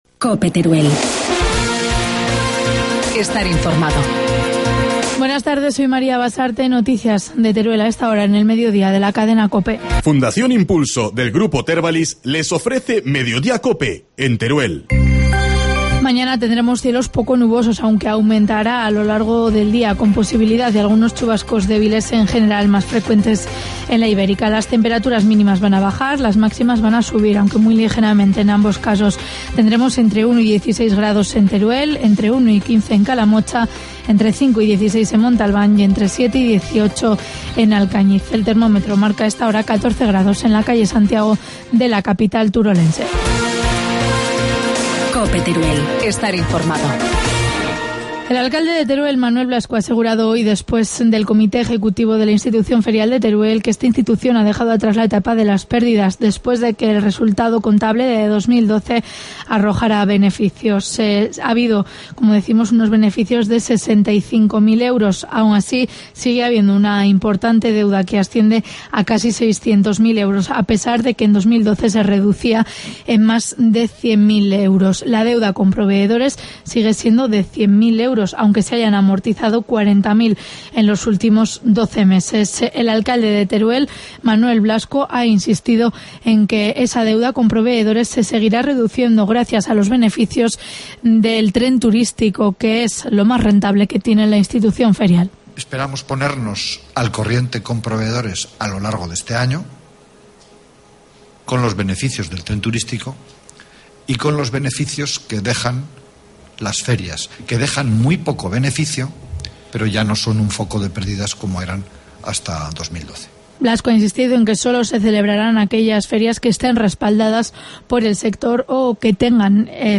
Informativo mediodía, lunes 20 de mayo